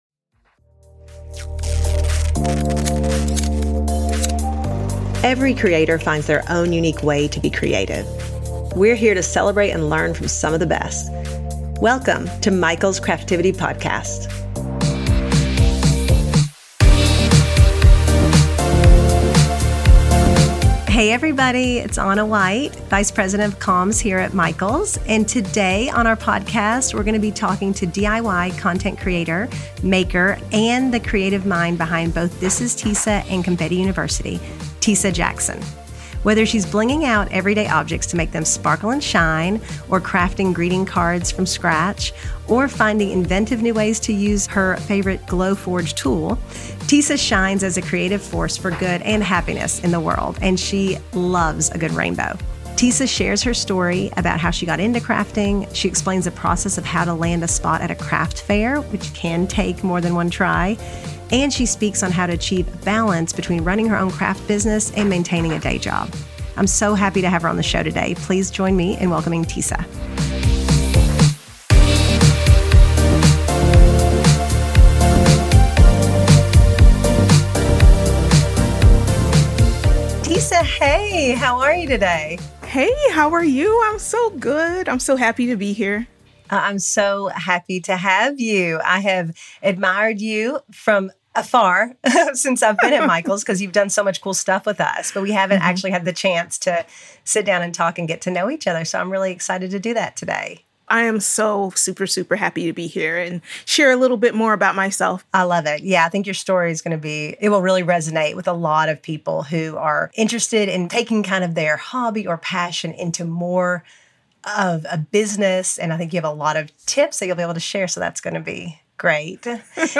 as she speaks with tech leaders, artists, influencers and entrepreneurs to uncover their best methods, recall their biggest lessons, and share in their love of creating.